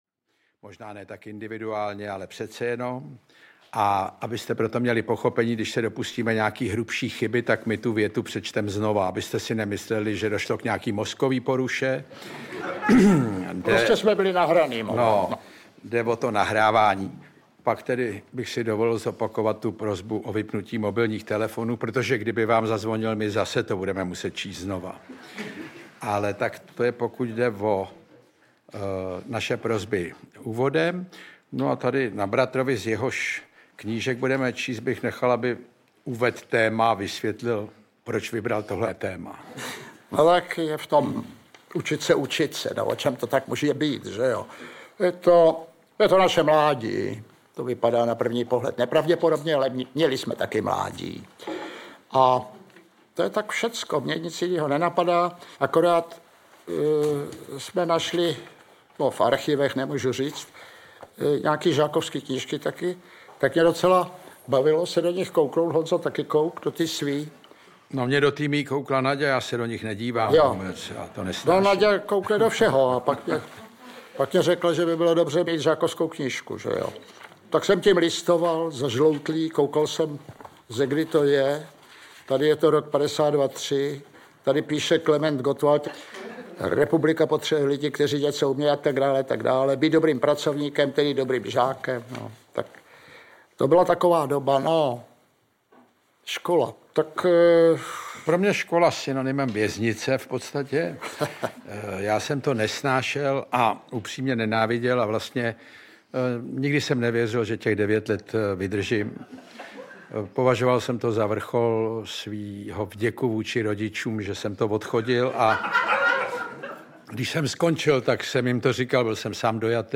Ukázka z knihy
Záznam představení z pražské Violy
• InterpretIvan Kraus, Jan Kraus